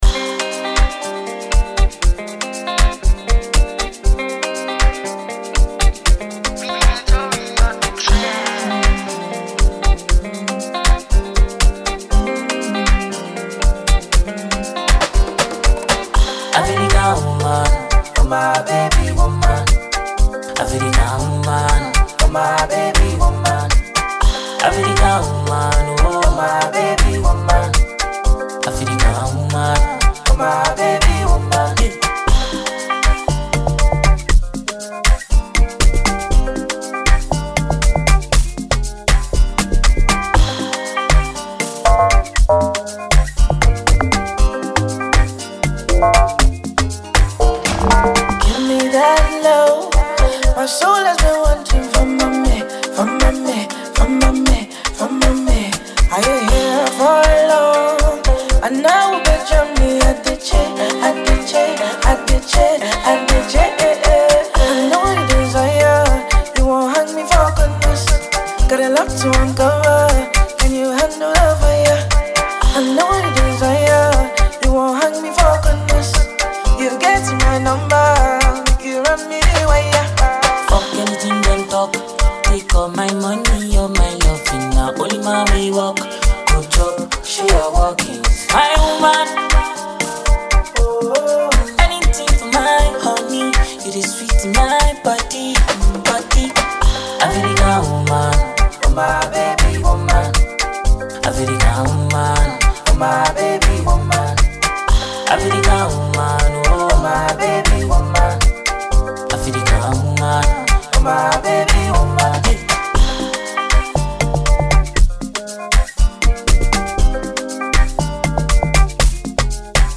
a talented Ghanaian songstress
This is a banger all day.